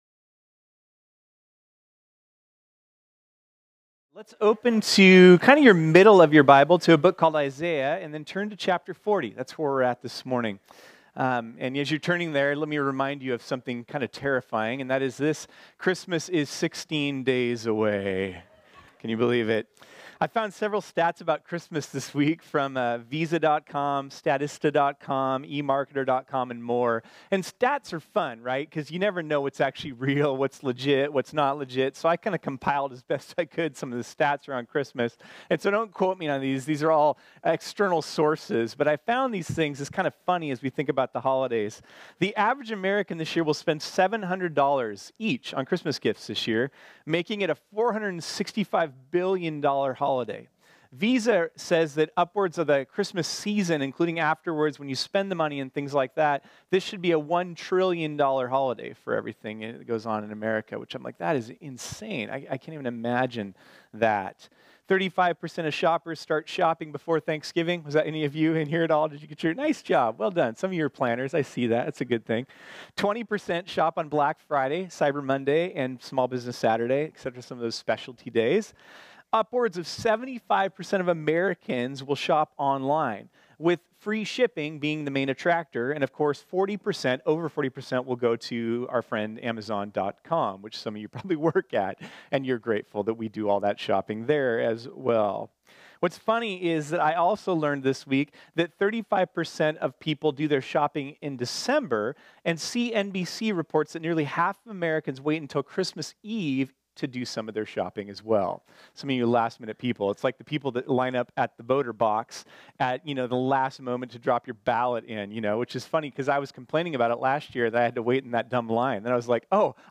This sermon was originally preached on Sunday, December 9, 2018.